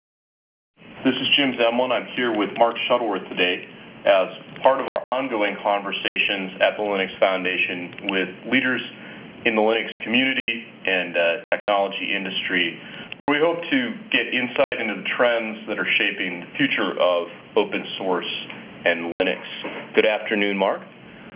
As reference speech fragment the part of Mark Shuttleworth's interview was given.
Then we pass this speech sample through wav2rtp with filter "independent packet losses" turned on and compare source and degraded file with pesqmain utility.
Table 1: Independent network losses influation on the output speech quality (G.729u)